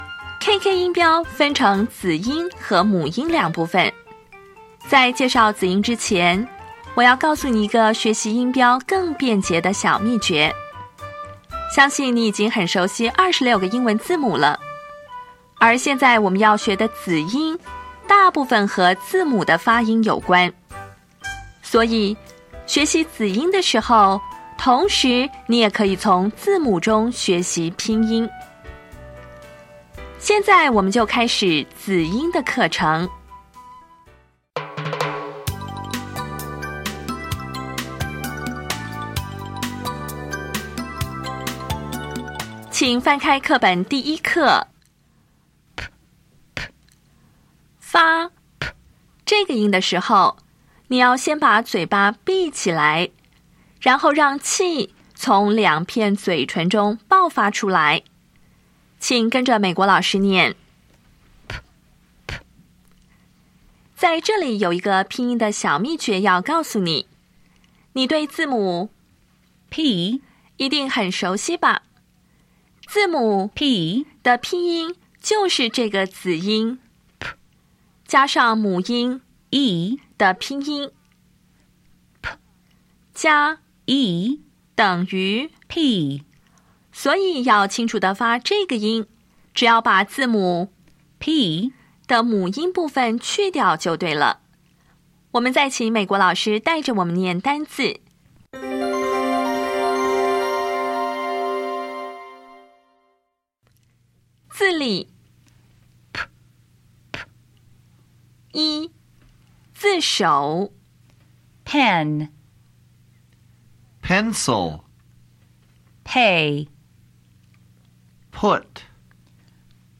当前位置：Home 英语教材 KK 音标发音 子音部分-1: 无声子音 [p]
音标讲解第一课
[pɛn]
[spik]